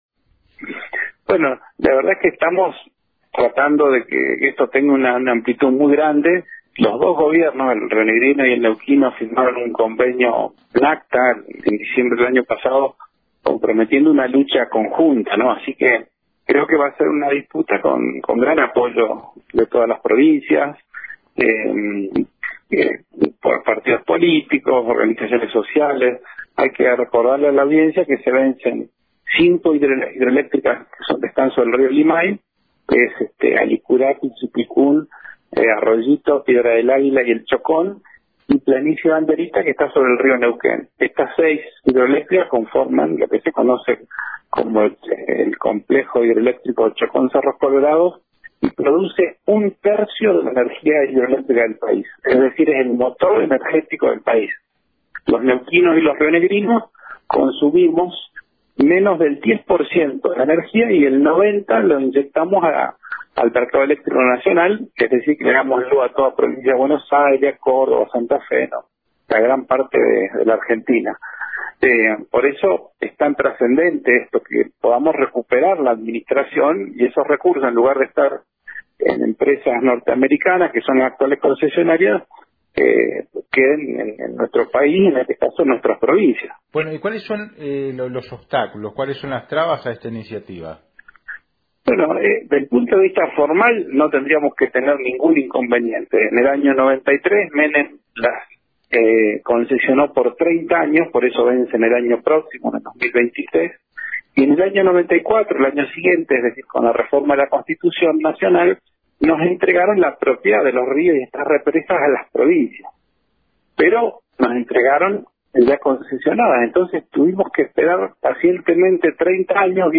Mariano Mansilla, Diputado Provincial neuquino de UNE, dialoga sobre los obstáculos para esta iniciativa y las ventajas que traería el traspaso.